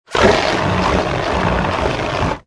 Звуки огнетушителя
На этой странице собраны звуки огнетушителя: шипение пены, нажатие рычага, распыление состава.
Звук поливающего пеной огнетушителя